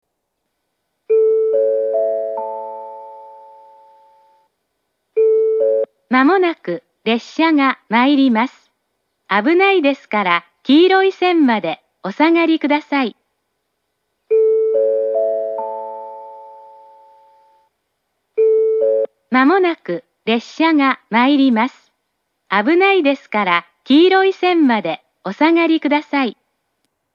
１番線接近放送